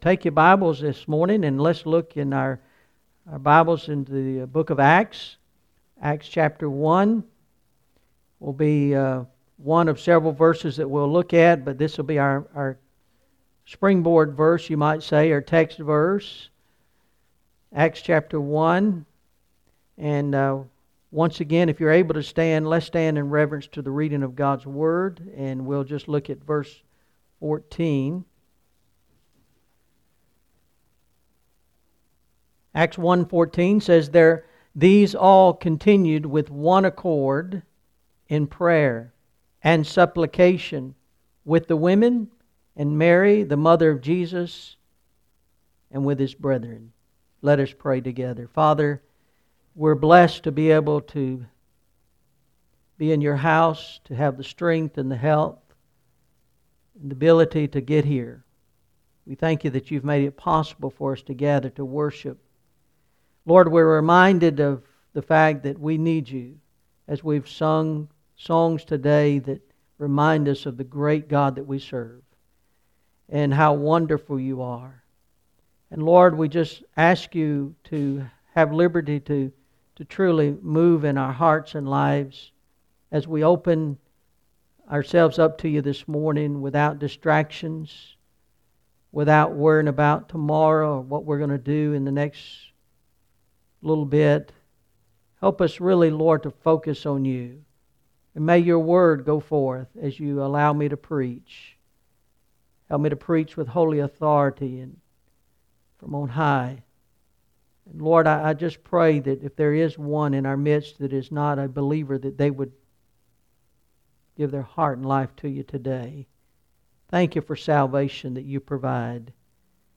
Message: Keeping The Church Alive In 2020!